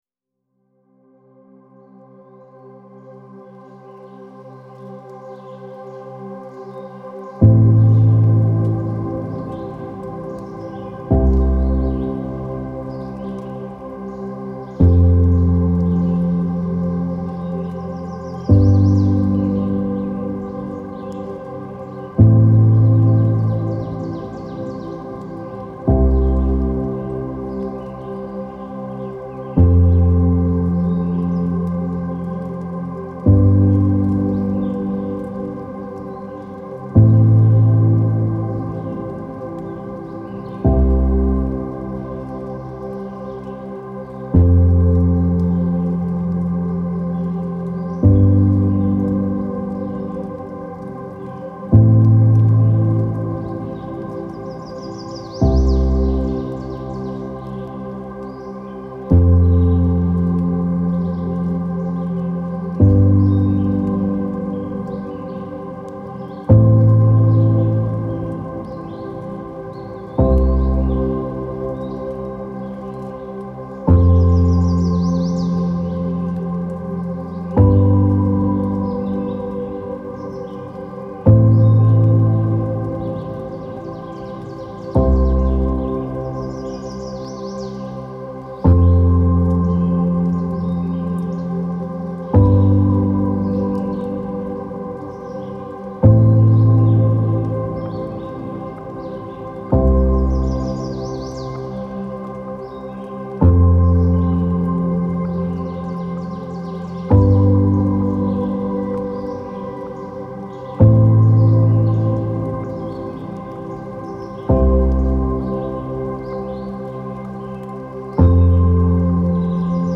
Piano-Music-for-Mindfulness-and-Meditation-With-Nature-Sounds.mp3